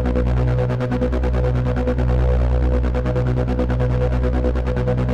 Index of /musicradar/dystopian-drone-samples/Tempo Loops/140bpm
DD_TempoDroneA_140-B.wav